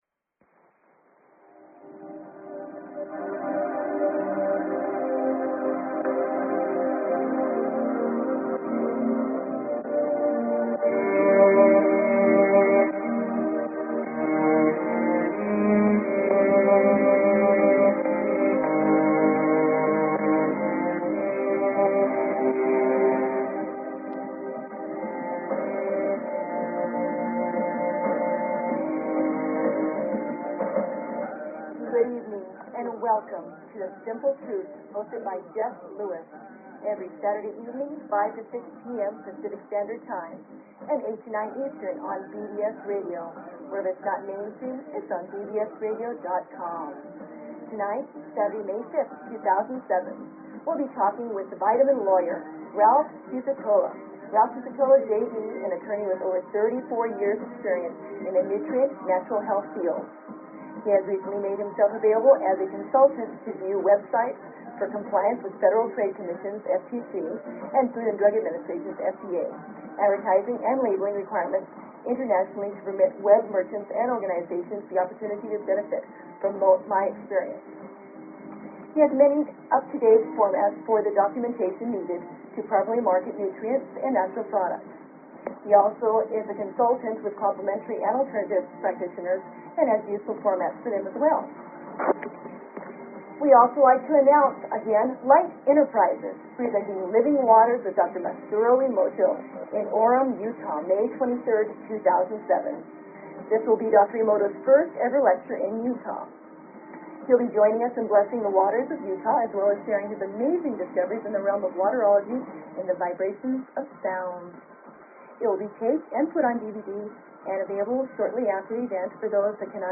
Talk Show Episode, Audio Podcast, The_Simple_Truth and Courtesy of BBS Radio on , show guests , about , categorized as
The Simple Truth will be focused around cutting-edge nutrition, health and healing products and modalities from around the world. We will be interviewing the worlds greatest health and nutrition experts regarding the latest research and developments in health and nutritional technology.